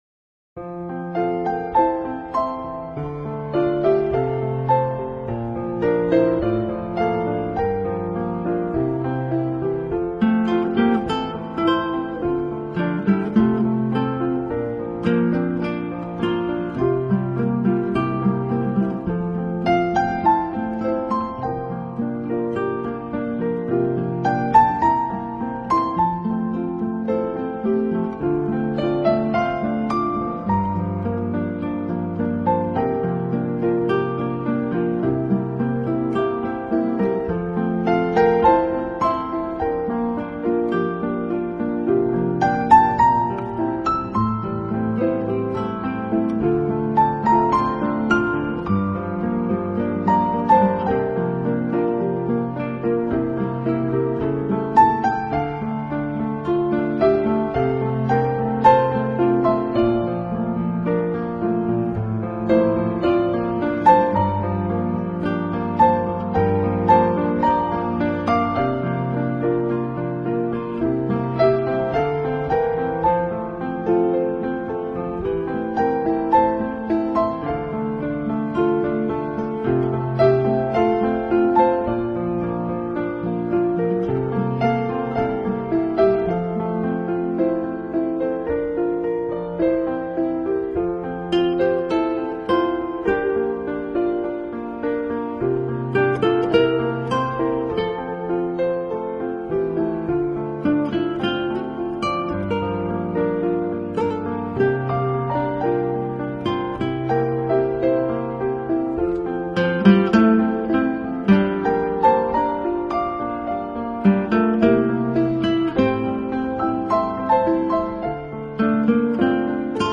【纯乐钢琴】
专辑语言：纯音乐
NewAge音乐专辑广受好评。